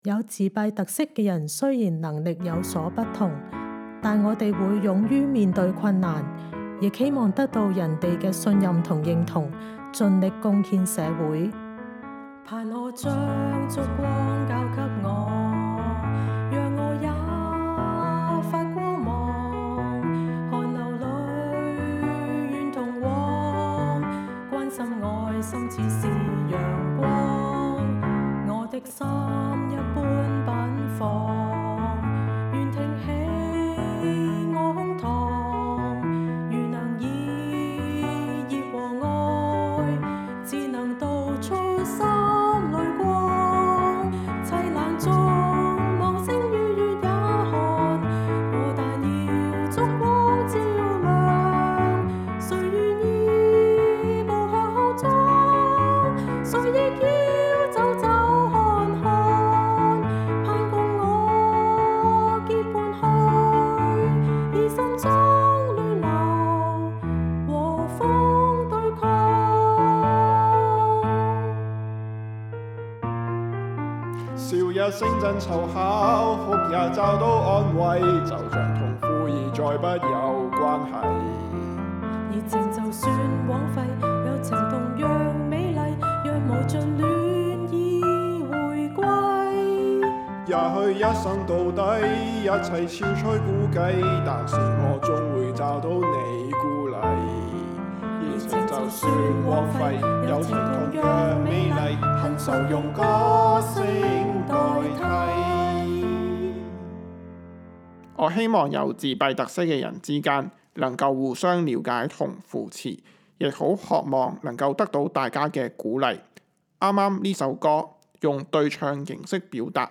一連四節的歌曲錄製活動歷時四個月，會員們攜手合作，從最初的構思、選曲、創作，到不斷練習與正式錄製，一起為新生會60週年獻上一份特別的賀禮。活動中大家各自發揮所長，分別有鋼琴、電結他、小提琴演奏，也有人作曲和演唱。